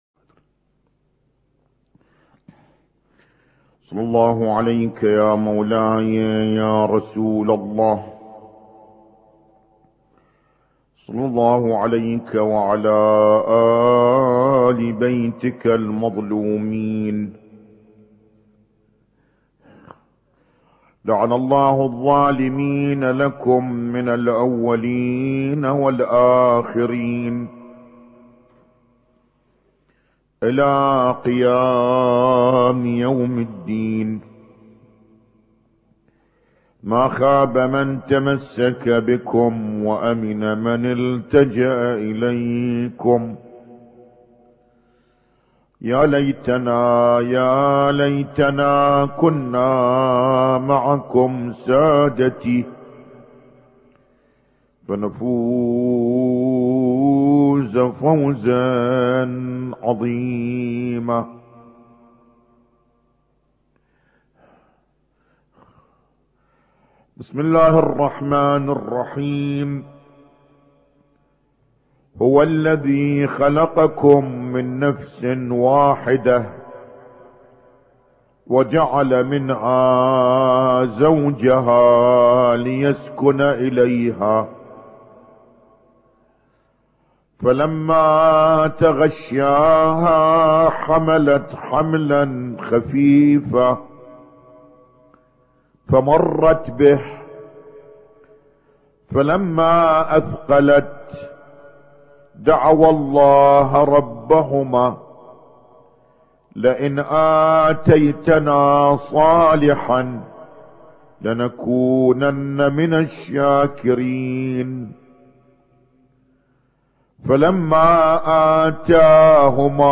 Husainyt Alnoor Rumaithiya Kuwait
اسم التصنيف: المـكتبة الصــوتيه >> المحاضرات >> المحاضرات الاسبوعية ما قبل 1432